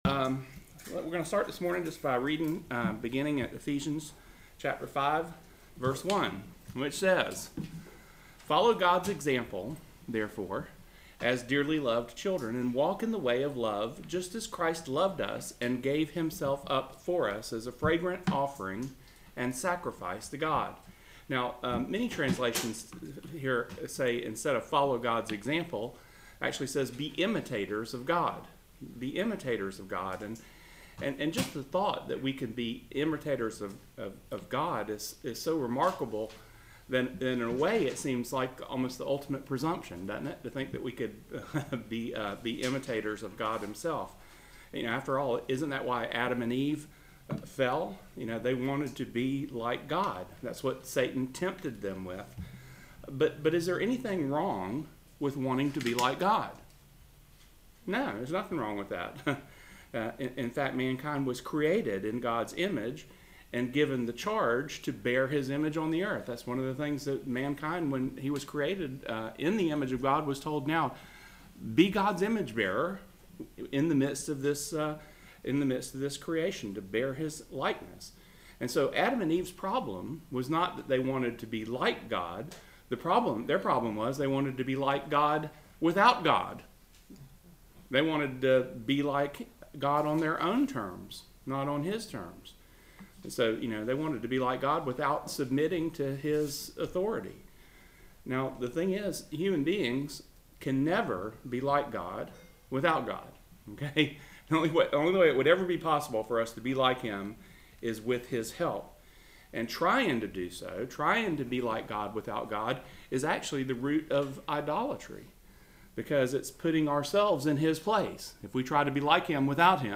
Bible Study 4-24-18 Follow God’s Example